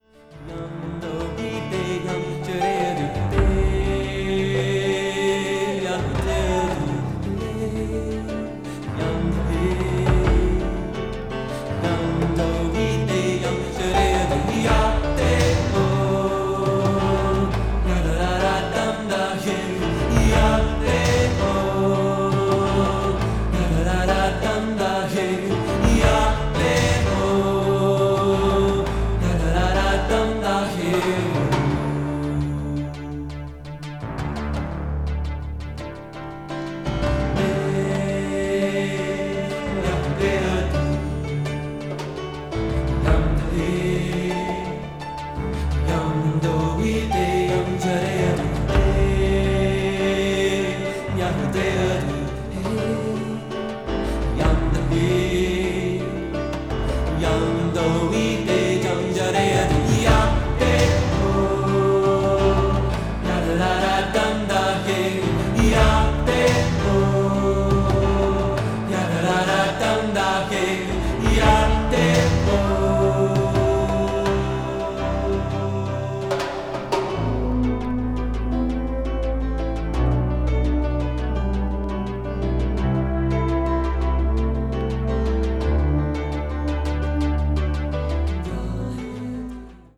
ambient   chamber music   electronic   new age   synthesizer